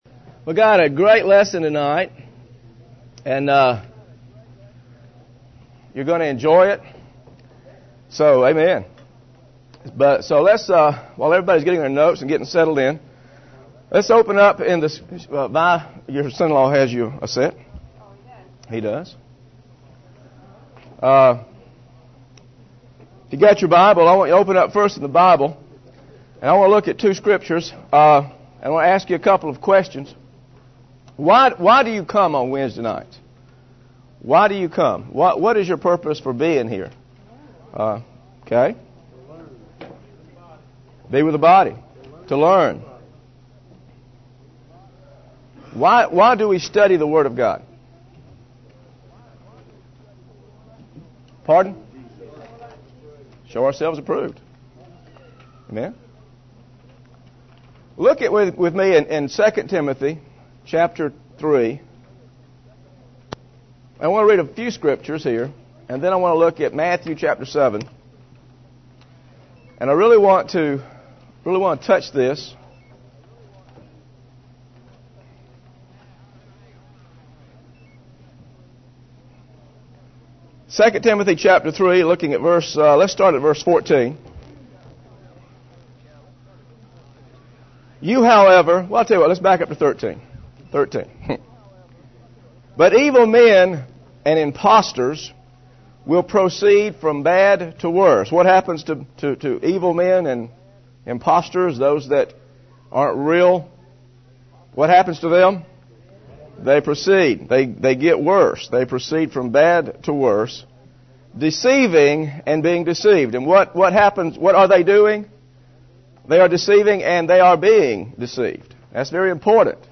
Insights on Moses' lineage and the impact of his first wife, Zipporah - Scriptures - sermon audio